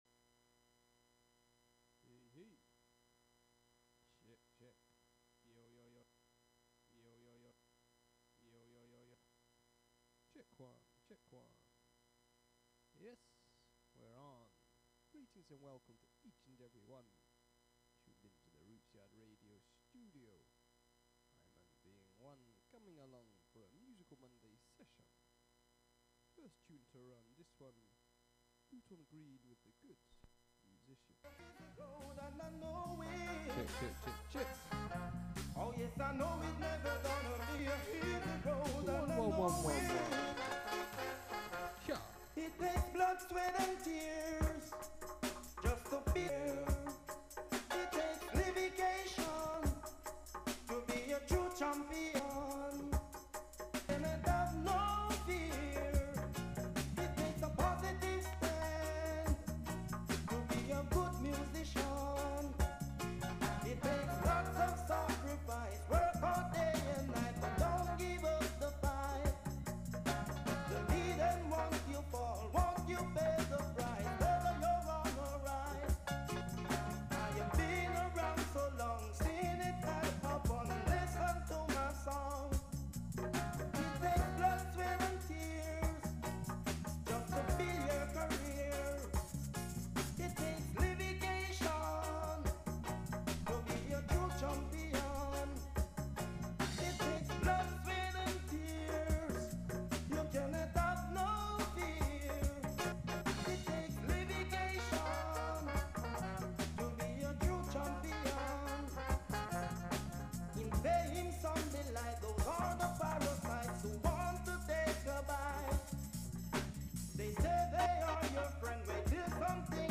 Bass Jam Session